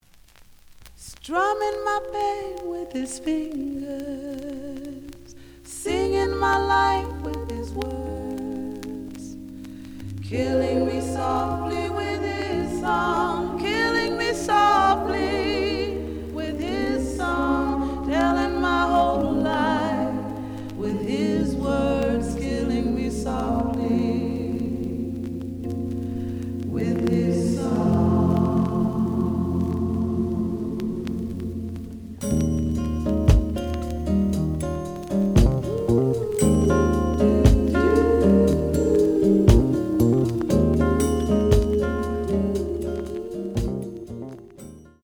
The audio sample is recorded from the actual item.
●Genre: Soul, 70's Soul
There is a bubble on beginning of A side, but almost good.)